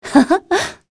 Scarlet-vox-Laugh.wav